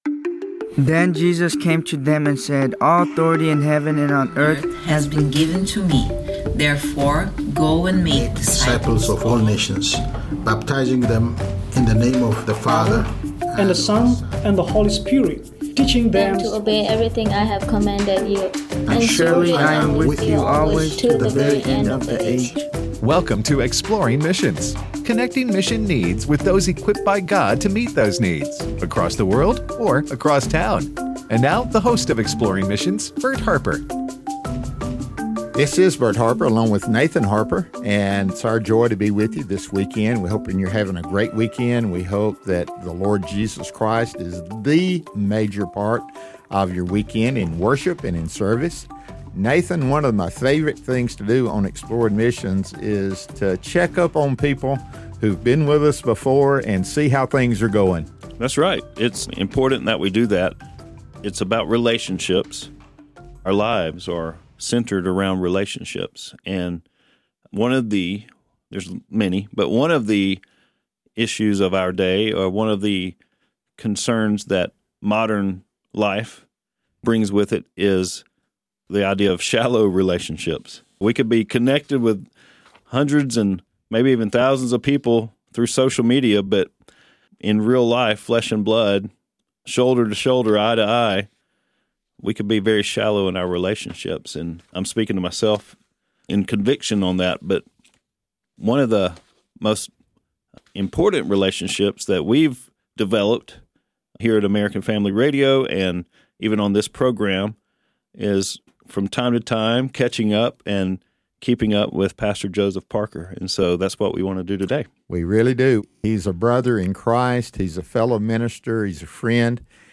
Vote To Support Life: A Conversation